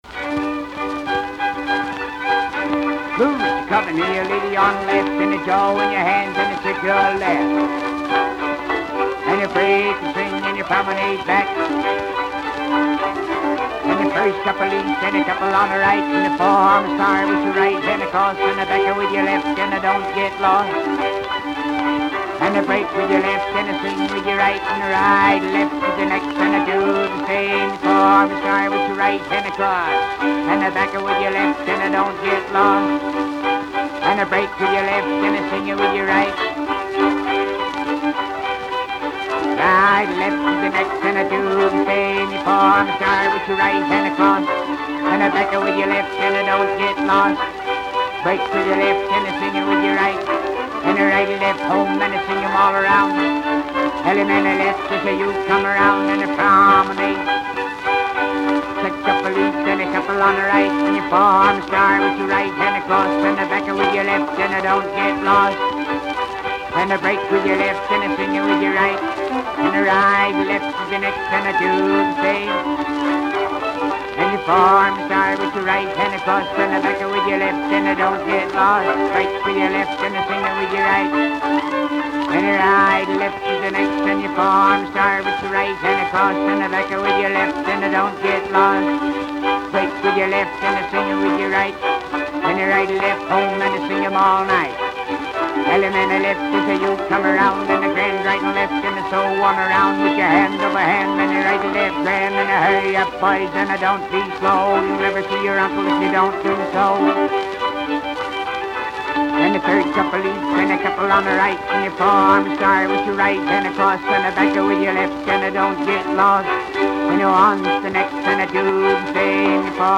thirteen of which had square dance calls.